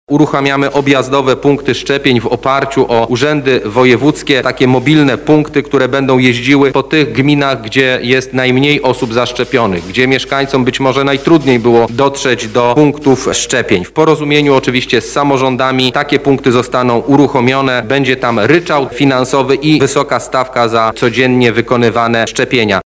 O drugim programie mówi szef kancelarii premiera Michał Dworczyk: